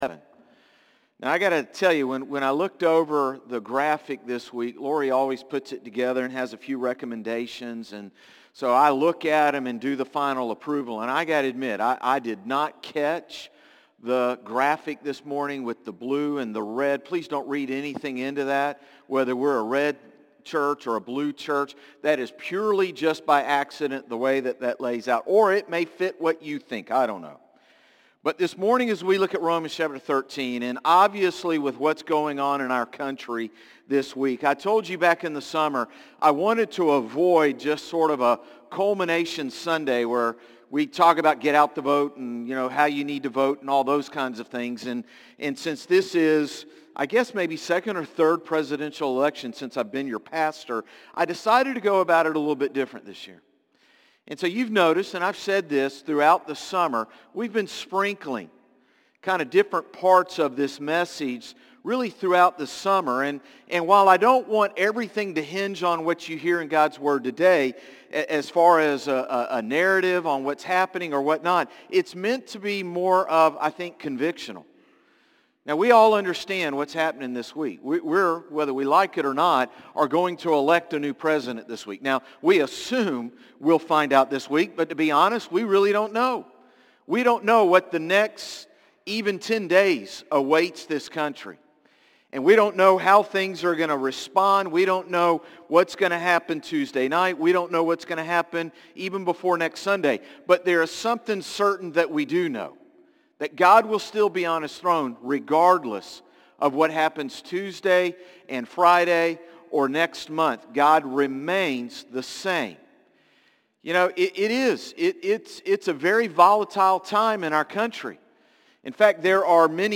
Sermons - Concord Baptist Church
Morning-Service-11-3-24.mp3